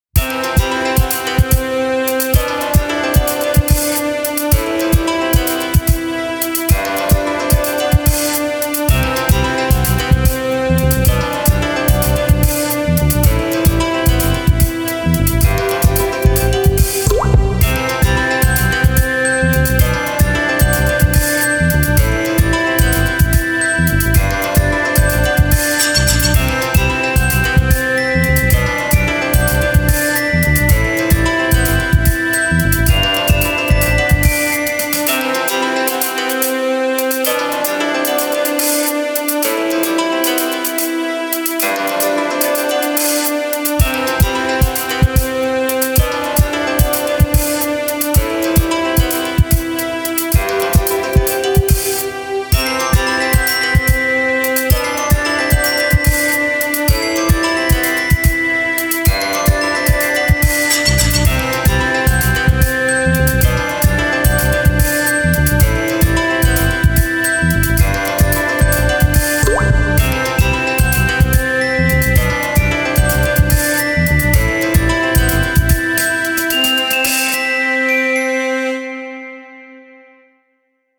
イージーリスニング
ポップス
シンセ
ベース
打楽器
明るい
爽やか